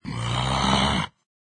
zombieVoice.ogg